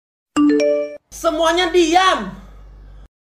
Kategori: Nada dering
nada-notifikasi-semuanya-diam-id-www_tiengdong_com.mp3